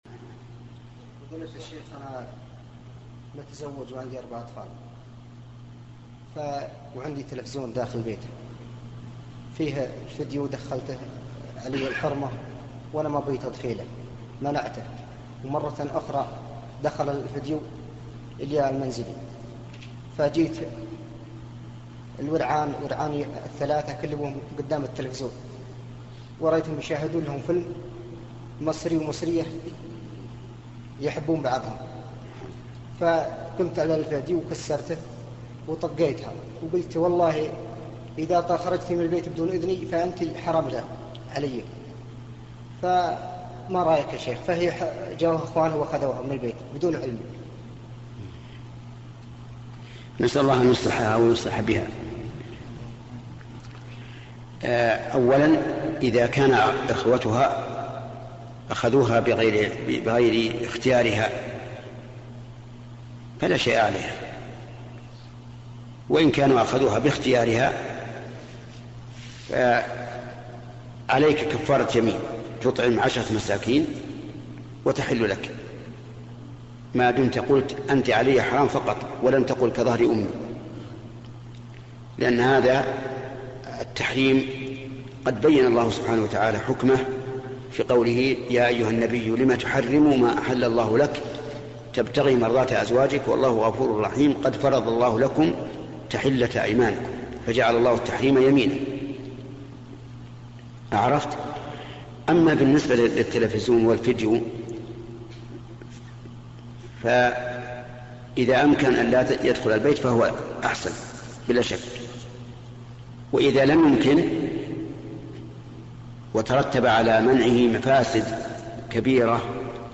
الجواب: 🔰 للعلامة الإمام 🔰